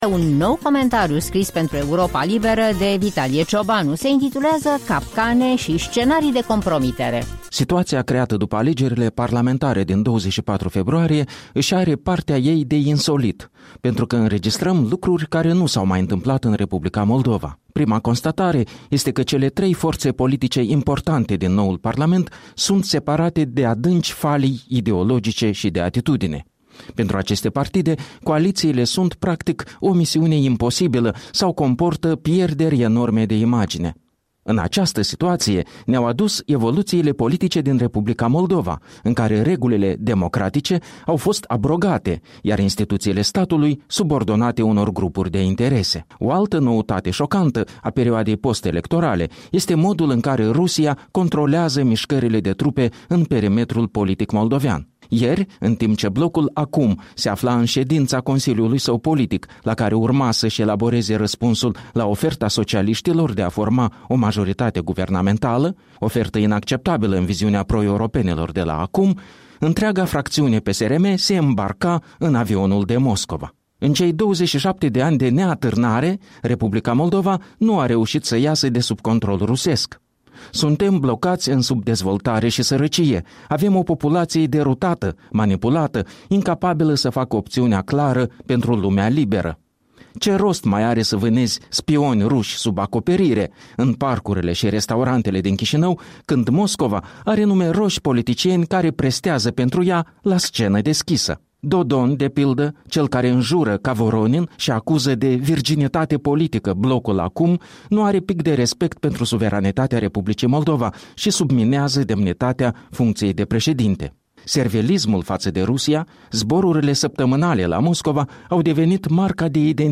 Un comentariu politic săptămînal